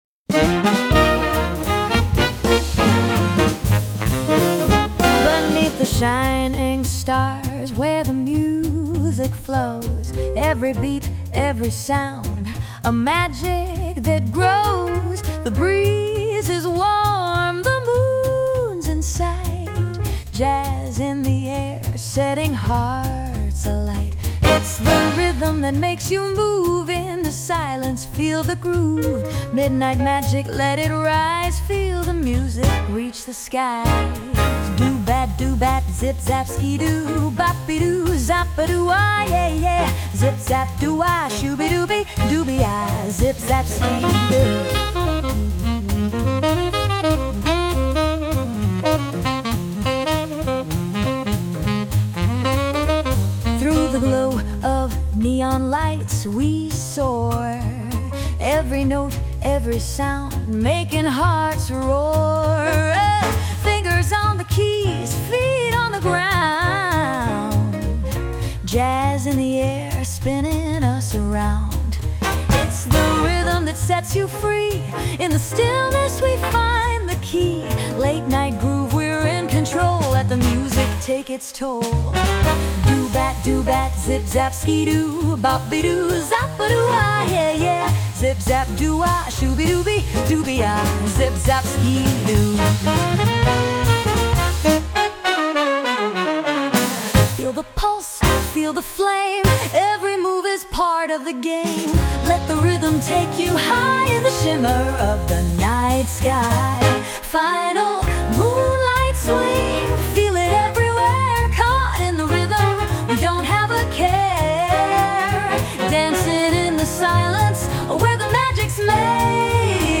Jazz, Swing